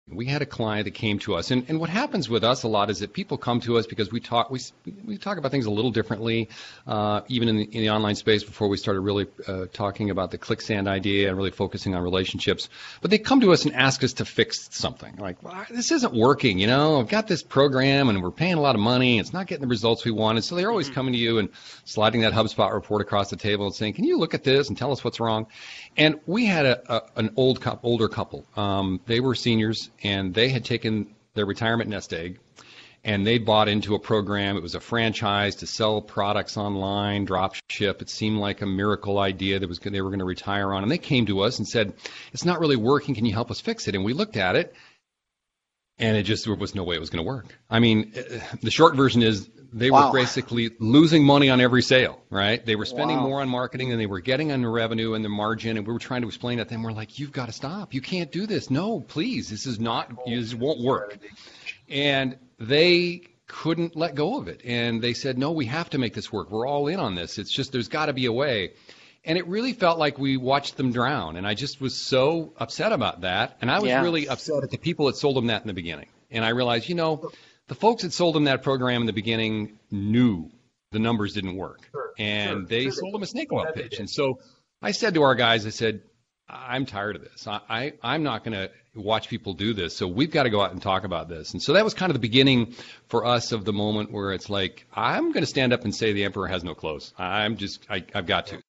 For the full interview, check out these links: